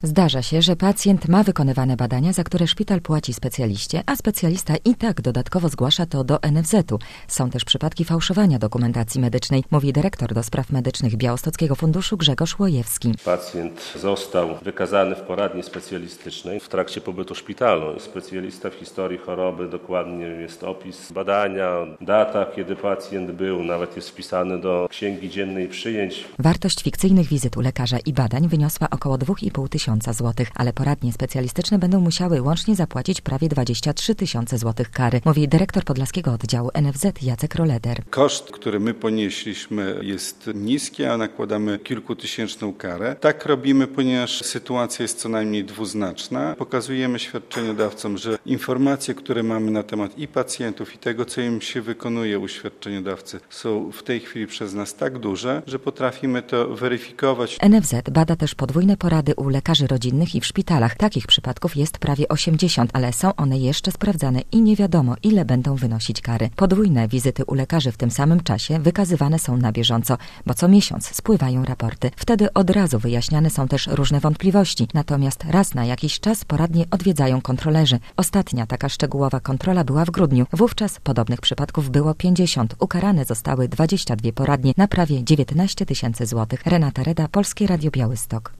"Wartość fikcyjnych wizyt u lekarza i badań wyniosła około 2,5 tysiąca, ale poradnie specjalistyczne będą musiały łącznie zapłacić prawie 23 tysiące złotych kary" - mówi dyrektor Podlaskiego Oddziału NFZ Jacek Roleder.